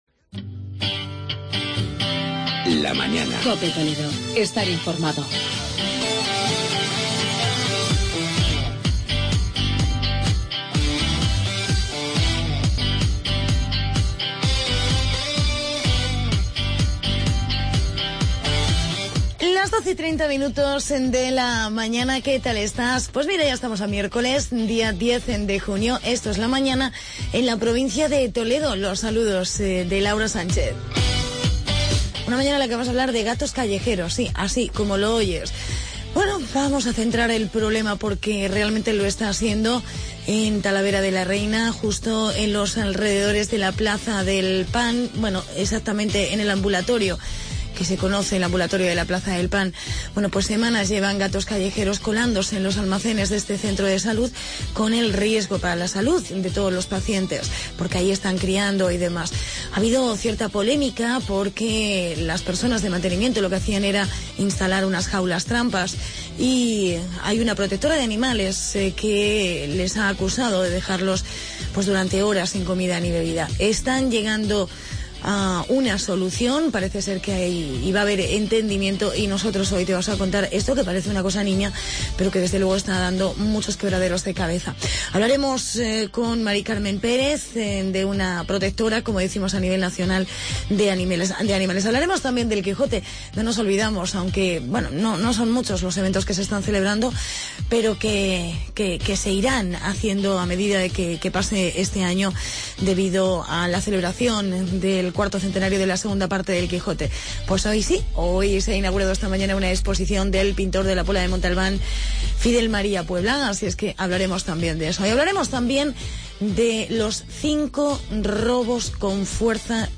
Hablamos del tema de los gatos en el Centro de Salud de Talavera. Reportaje